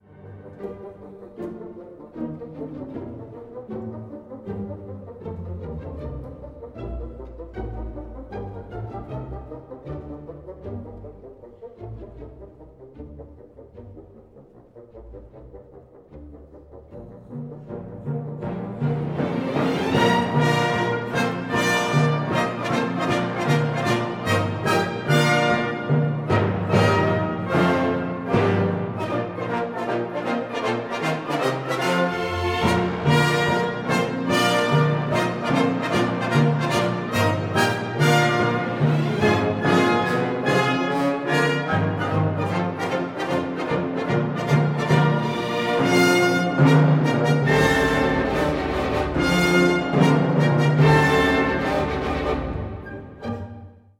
Allegretto non troppo
in the Philharmonie im Gasteig, Munich, Germany